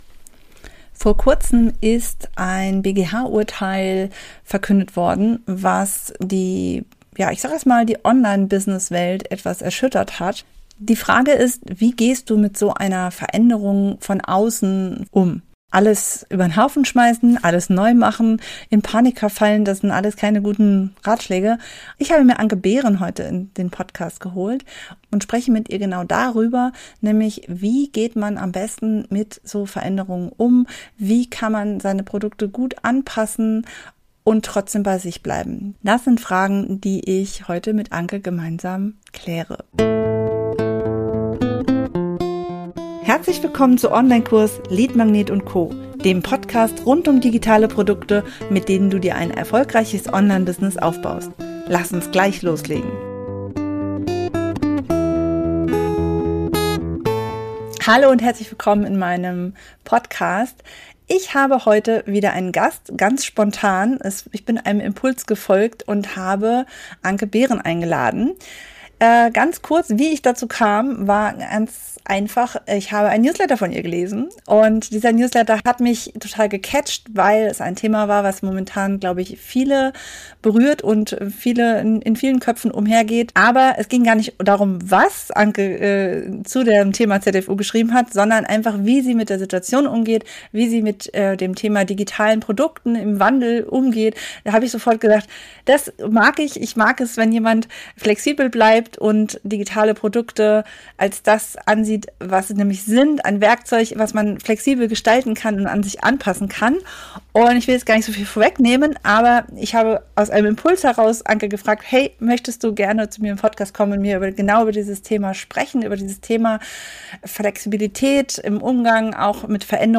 Ein ehrliches, ermutigendes Gespräch über Haltung, Anpassung und die Freiheit, auch im Chaos neue Chancen zu sehen.